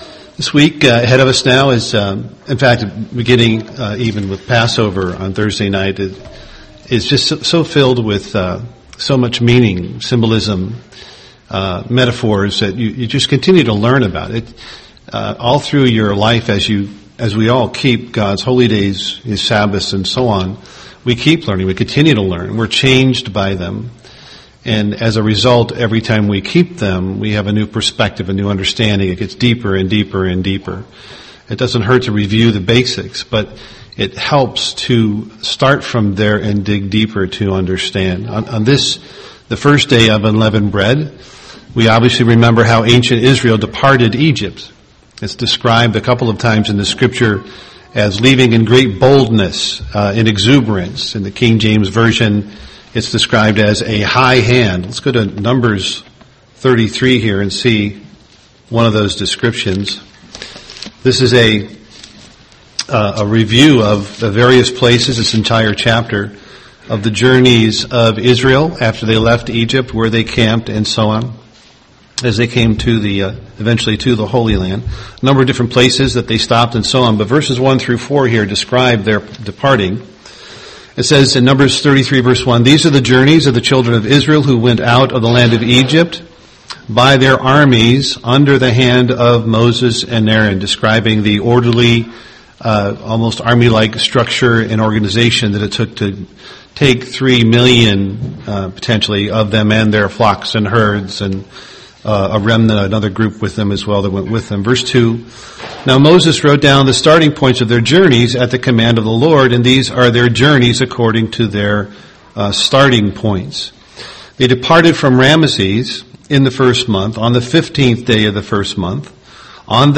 UCG Sermon Egypt Unleavened Bread Studying the bible?